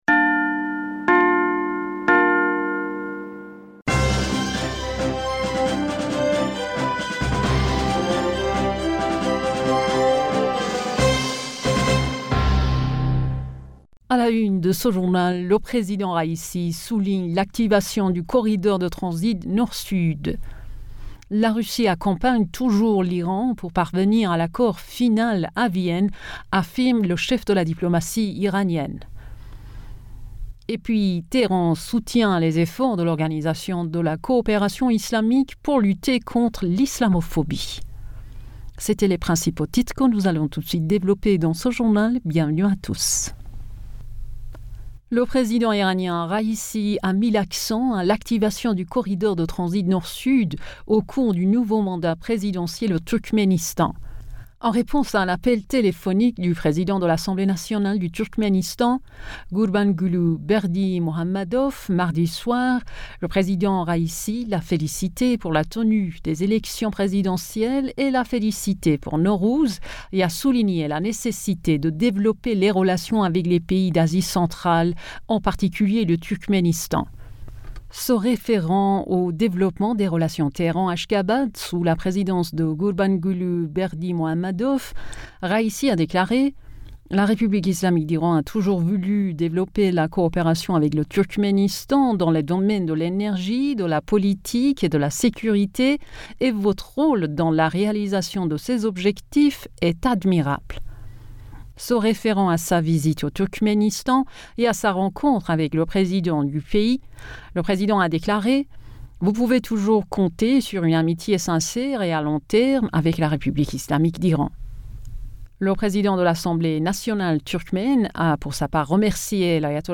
Bulletin d'information Du 16 Mars 2022